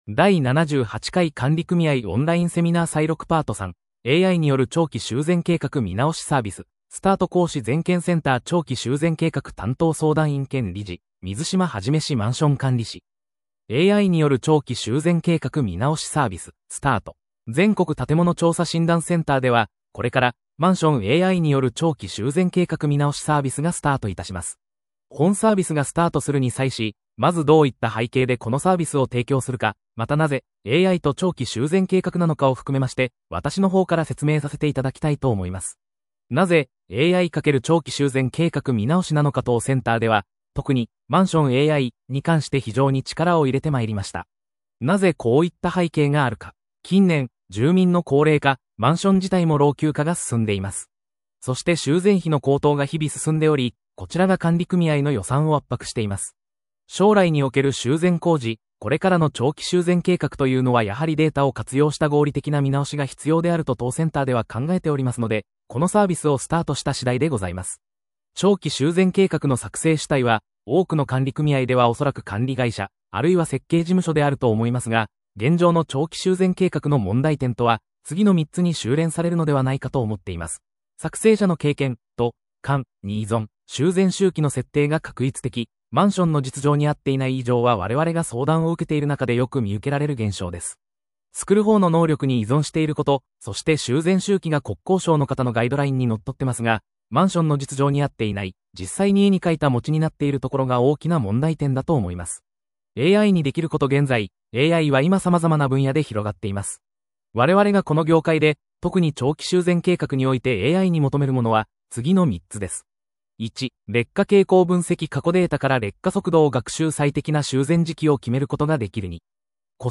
第78回管理組合オンライン・セミナー採録Part.3